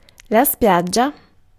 Ääntäminen
France: IPA: [ɡʁɛv]